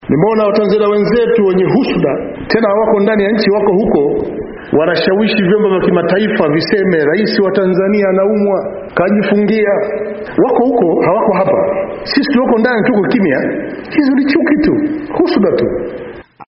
Majaliwa ambaye alikuwa akizungumza wakati wa sala ya Ijumaa mjini Njombe, kusini mwa Tanzania aliwataka Watanzania kupuuza ripoti zinazosambaa ambazo zinaeleza kuwa kiongozi wao ni mgonjwa.